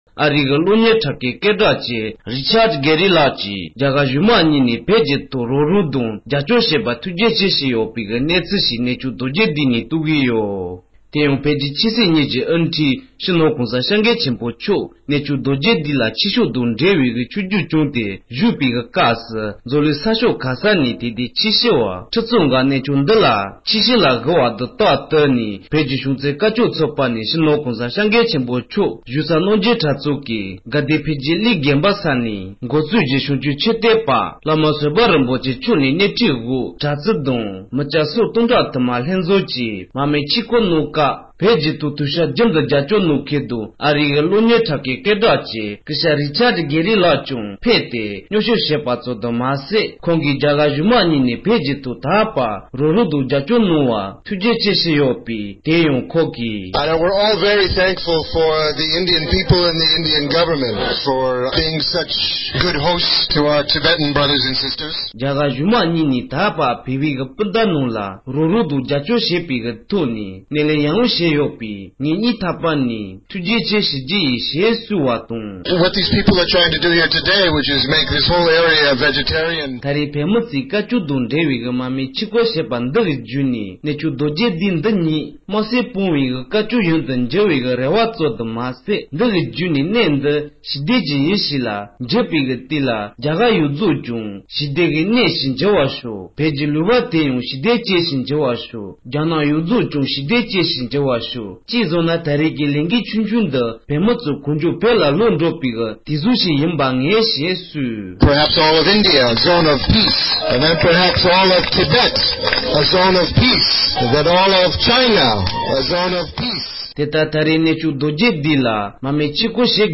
རྒྱ་གར་གཞུང་མང་ལ་ཐུཊ་རྗེ་ཆེ། དུས་རྒྱུན་དུ་བོད་དོན་རྒྱབ་སྐྱོར་བ་སྐུ་ཞབས་རི་ཅརྜ་དགེར་ལགས་གནས་མཆོག་རྡོ་རྗེ་གདན་དུ་ཞལ་འདོན་གནང་བཞིན་པ།
སྒྲ་ལྡན་གསར་འགྱུར།